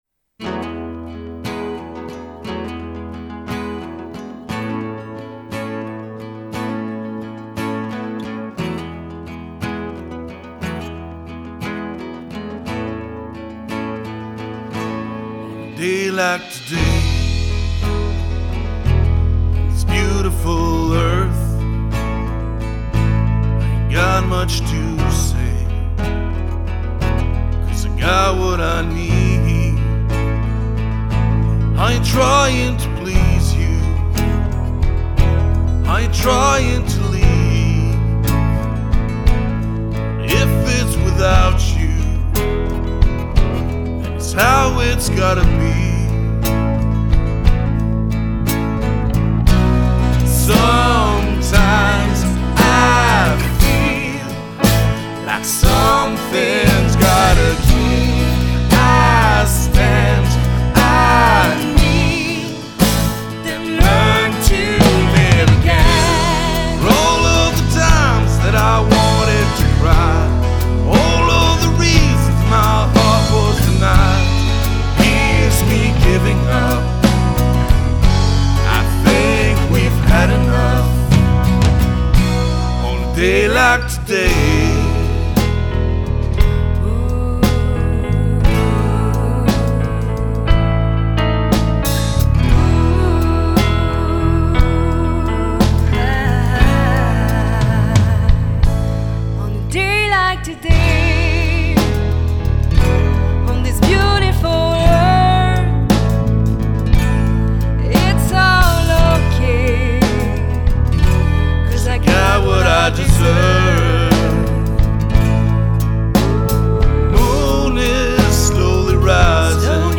guitare et voix principale
Voix et clavier
Batterie
Basse électrique